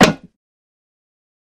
Звуки бидона
Звук накрыли металлической крышкой